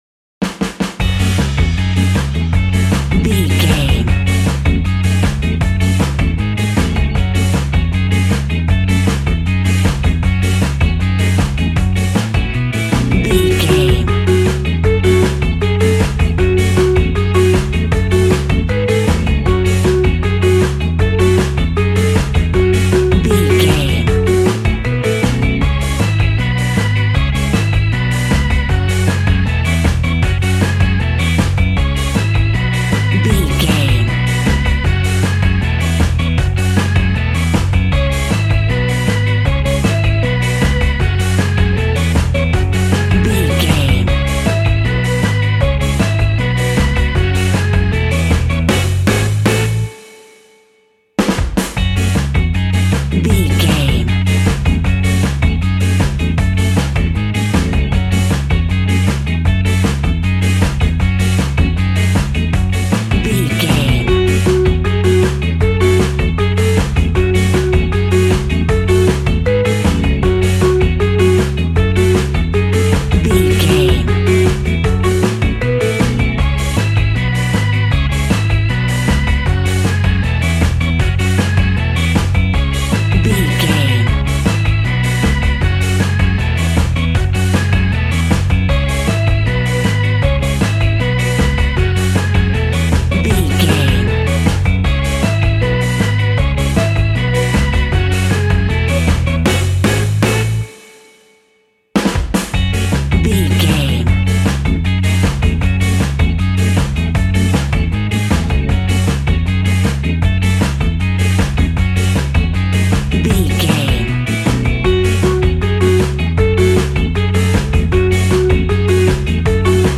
Classic reggae music with that skank bounce reggae feeling.
Aeolian/Minor
F#
reggae instrumentals
laid back
chilled
off beat
drums
skank guitar
hammond organ
transistor guitar
percussion
horns